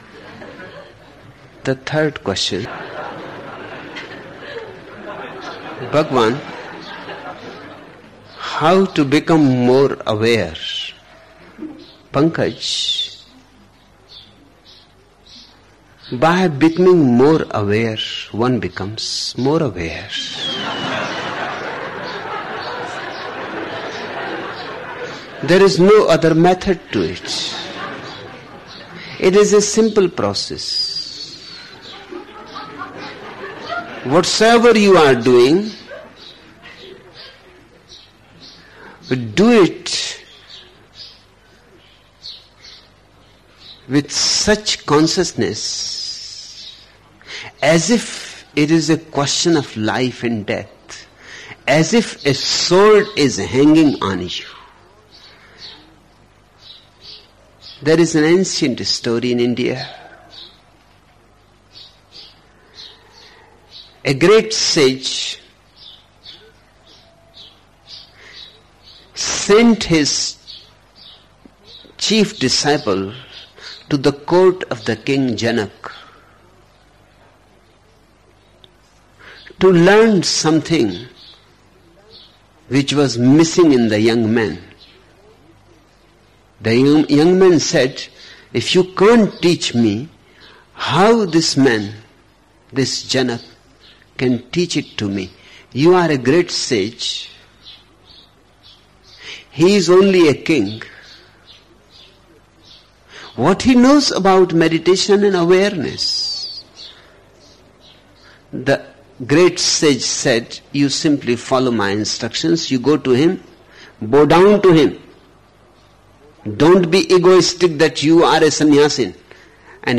Each program has two parts, Listening Meditation (Osho discourse) and Satsang Meditation.
The Listening Meditations in the following programs include discourses given by Osho from 1974 – 1988 and were given in Pune, India; Rajneeeshpuram, OR; Kathmandu, Nepal; Punte del Este, Uruguay; Mumbai and Pune, India.